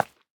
Minecraft Version Minecraft Version latest Latest Release | Latest Snapshot latest / assets / minecraft / sounds / block / candle / break4.ogg Compare With Compare With Latest Release | Latest Snapshot
break4.ogg